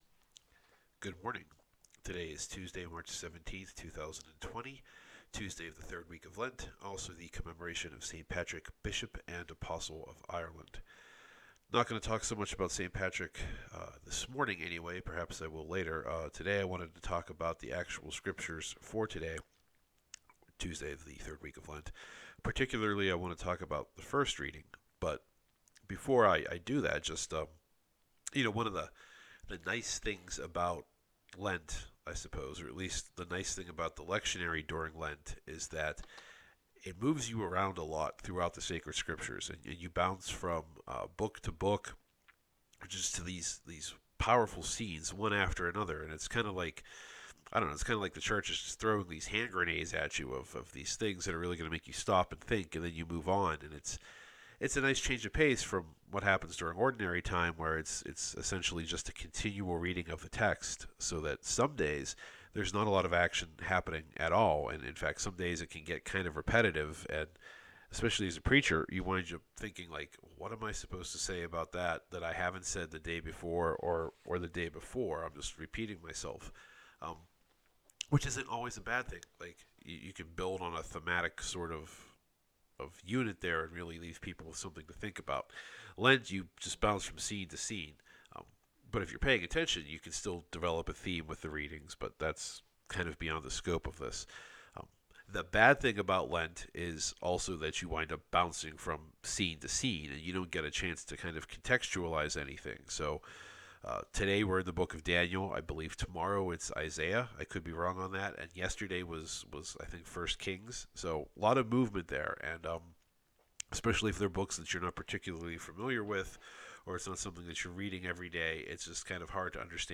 Here’s a brief reflection (well, 12 minutes – not as brief as I thought) on today’s readings from Mass, which you can find by clicking here.